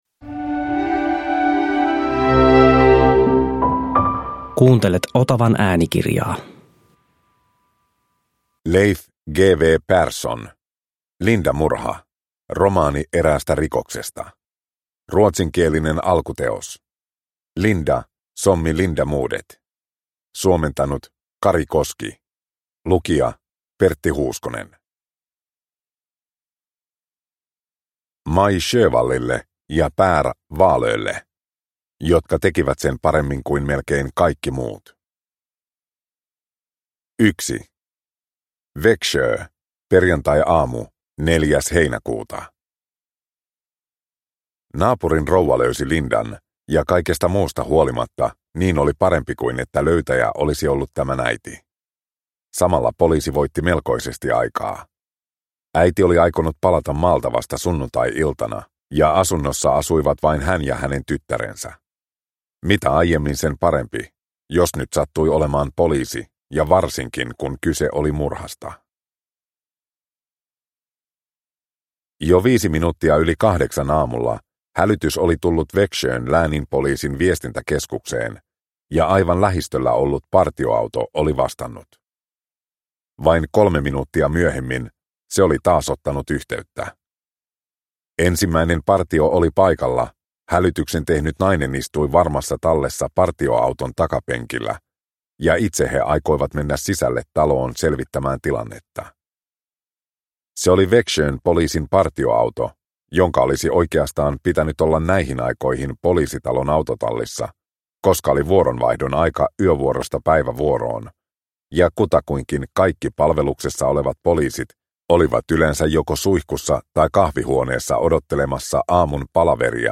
Lindamurha – Ljudbok – Laddas ner